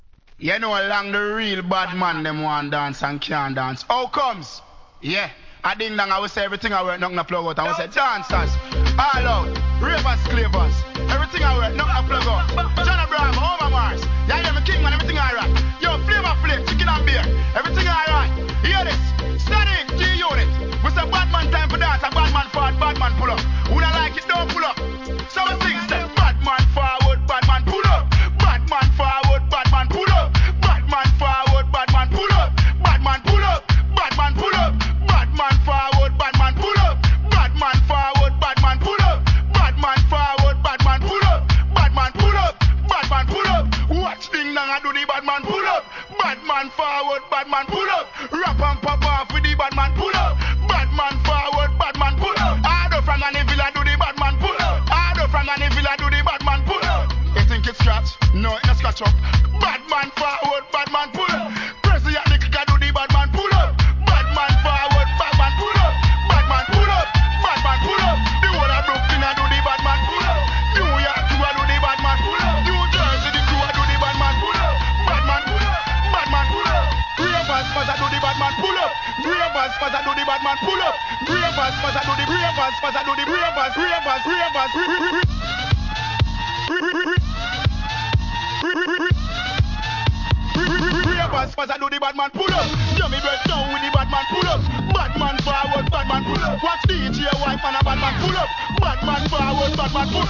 HOUSE〜EDM REMIX